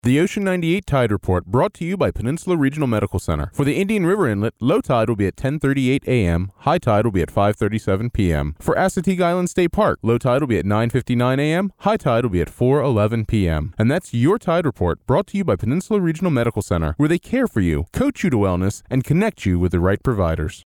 MXL 770 Condenser Microphone, Focusrite Scarlett 2i4 Audio Interface, DBX 286S Preamp/Processor, Adobe Audition Creative Cloud.
Dry Vocal Tracks
Tide-Report-Dry.mp3